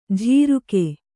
♪ jhīruke